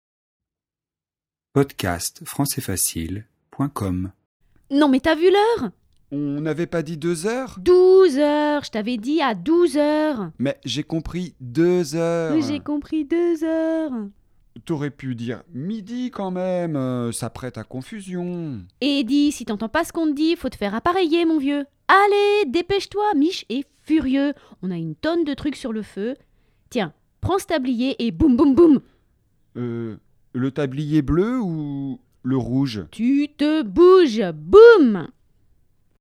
• phonétique : opposition deux douze
• registre de langue : français familier, prononciation de TU
🔷 DIALOGUE :